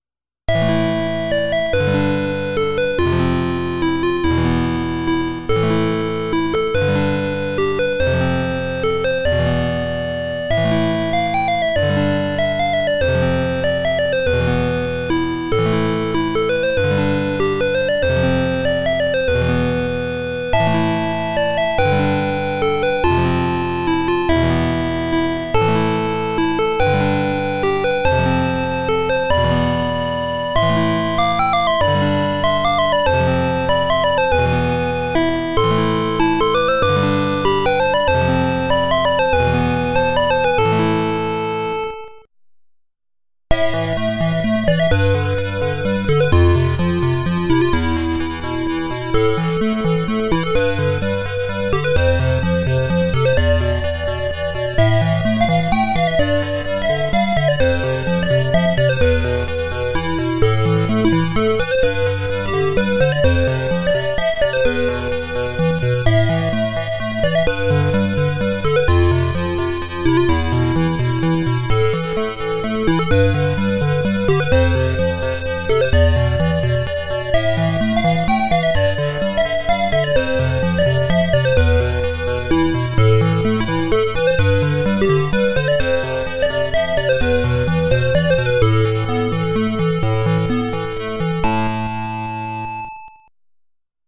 being played through the Apple II’s Mockingboard